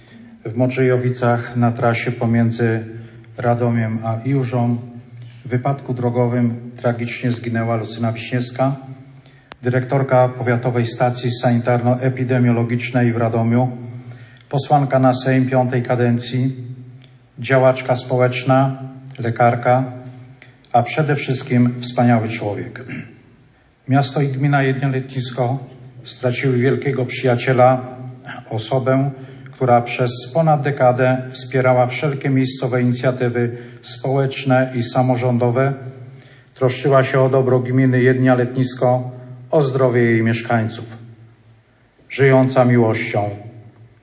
O przyznanie tytułu Honorowej Obywatelki Jedlni – Letniska wnioskował Piotr Leśnowolski burmistrz miasta.